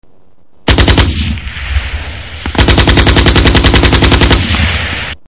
Выстрел из автоматной винтовки